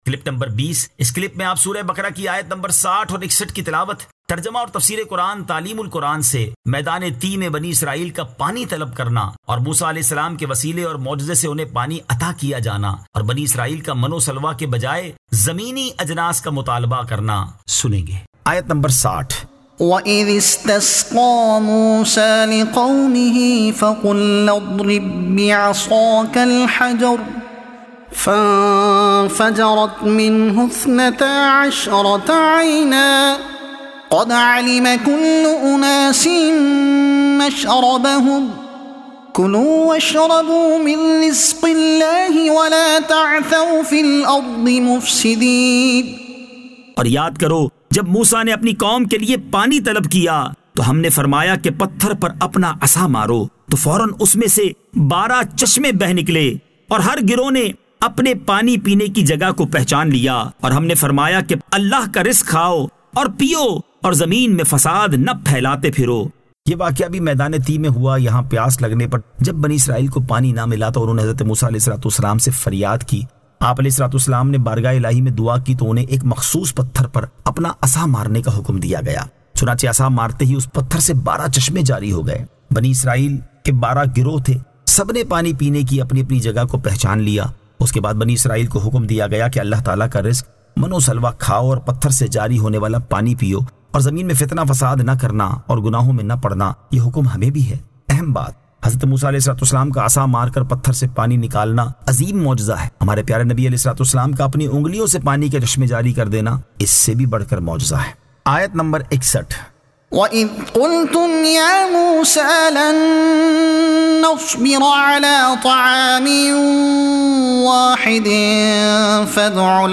Surah Al-Baqara Ayat 60 To 61 Tilawat , Tarjuma , Tafseer e Taleem ul Quran